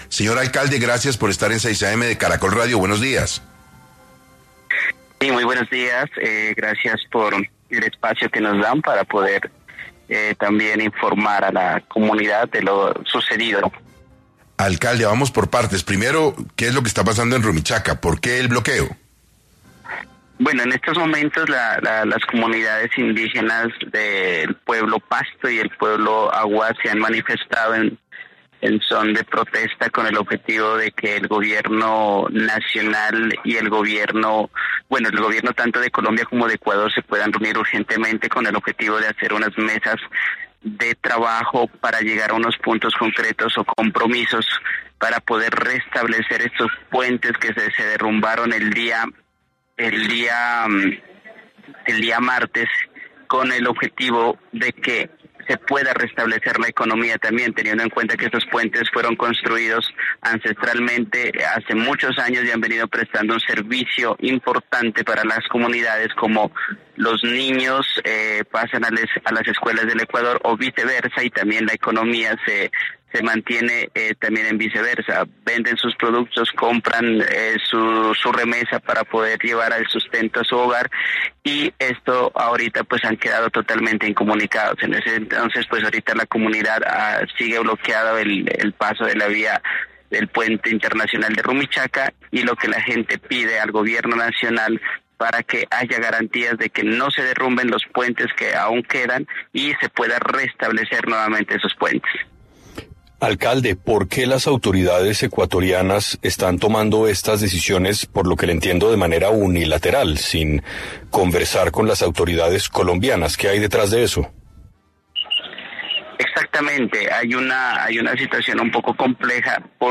En 6AM de Caracol Radio estuvo Andrés Tapié, alcalde de Cumbal, para hablar sobre cuánto son las pérdidas y las consecuencias que genera el cierre del puente Internacional de Rumichaca ante los bloqueos de indígenas.